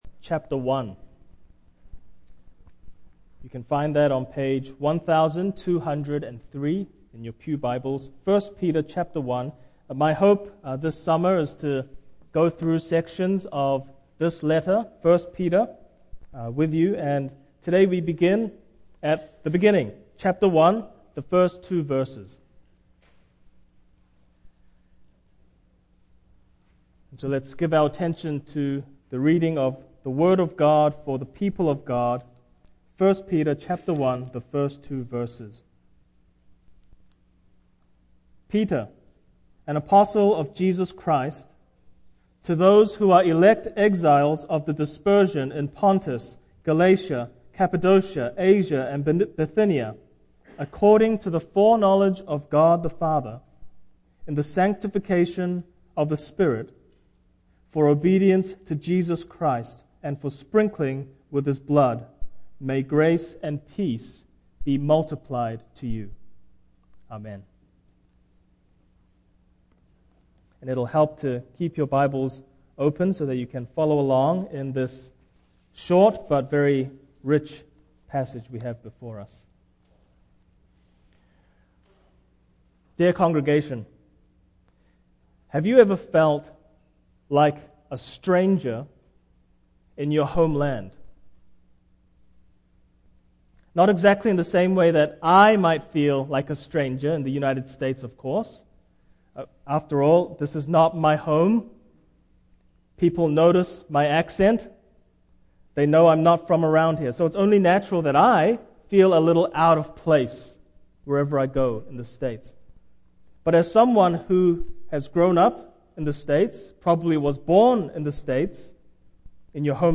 Life Together in Light of the End (end of sermon clipped off due to technical difficulties)